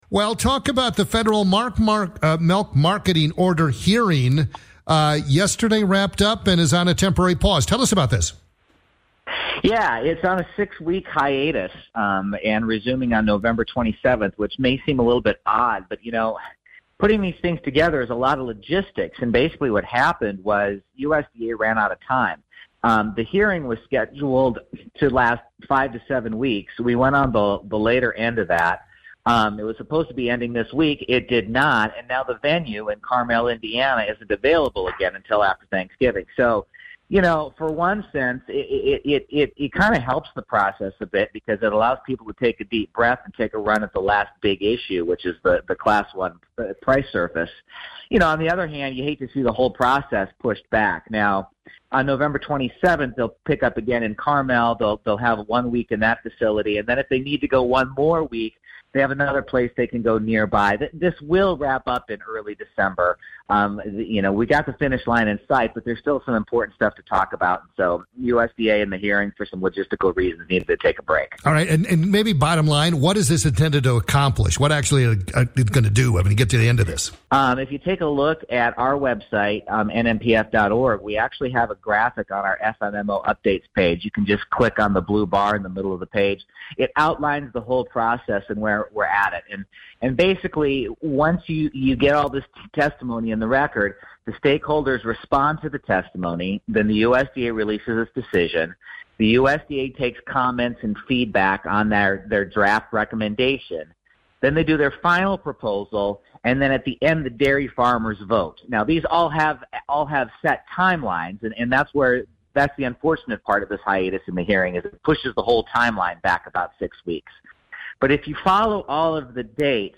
in an interview with WEKZ radio, Janesville, WI.